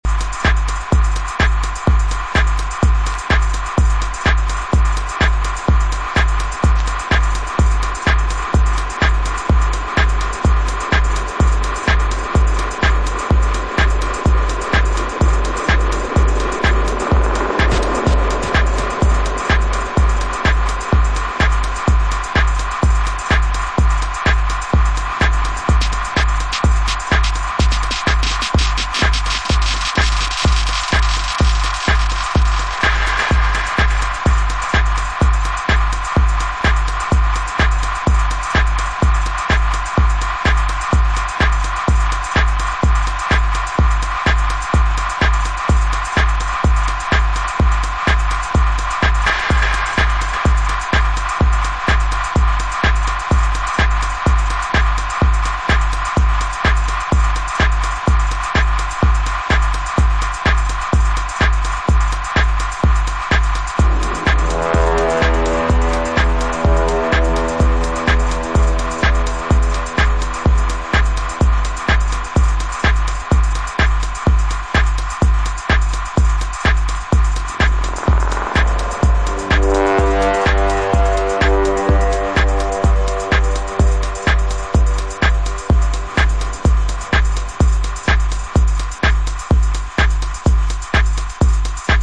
Cutting edge house music